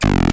电瓶玩具猕猴桃 " 错误
描述：从一个简单的电池玩具中录制的，是用一个猕猴桃代替的音调电阻！
标签： 音乐学院-incongrue 低音 circuit-弯曲
声道立体声